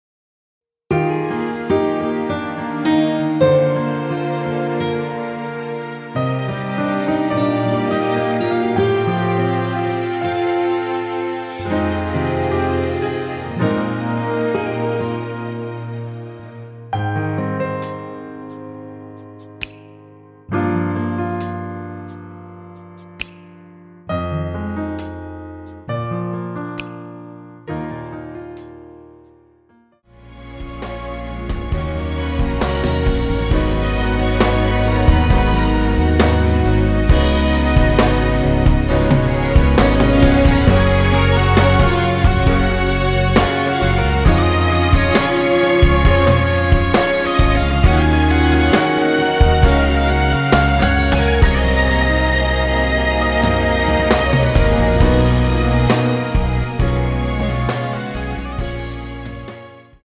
(-1) 내린 MR 입니다.(미리듣기 참조)
Eb
◈ 곡명 옆 (-1)은 반음 내림, (+1)은 반음 올림 입니다.
앞부분30초, 뒷부분30초씩 편집해서 올려 드리고 있습니다.